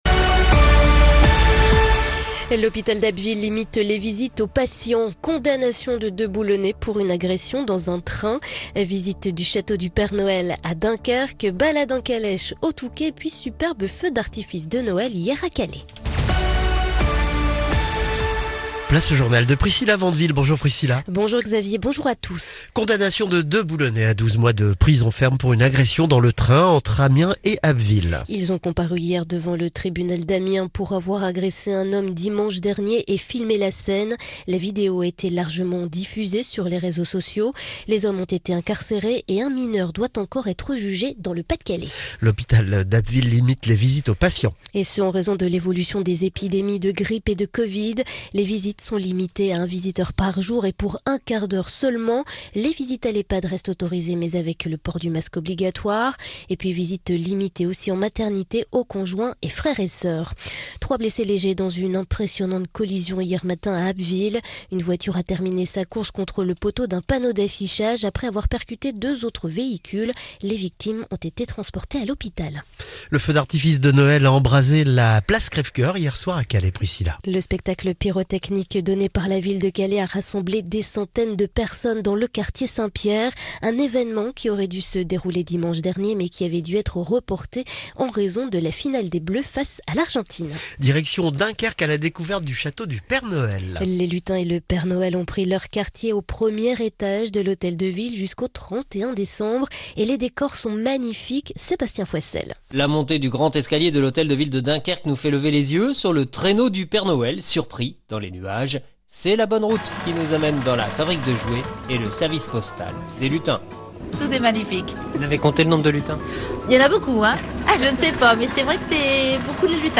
Le journal Côte d'Opale et Côte Picarde du jeudi 22 décembre